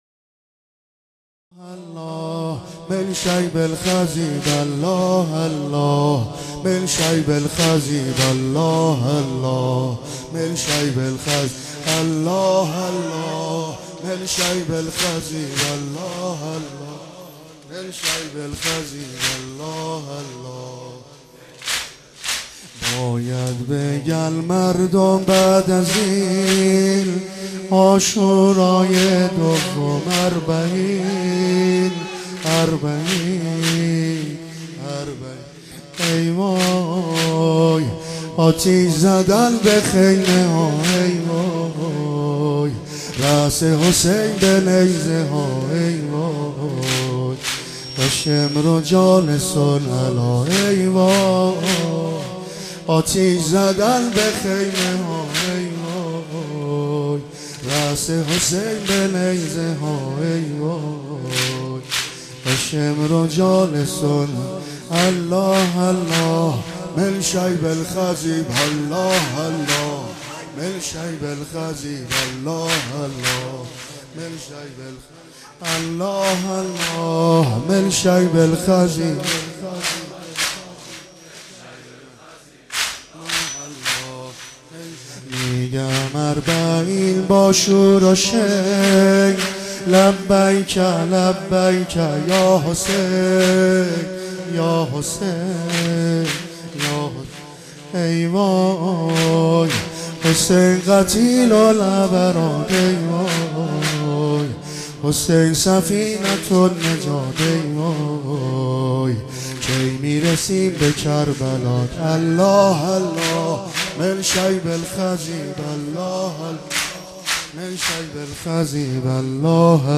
ا ا مداحی عبدالرضا هلالی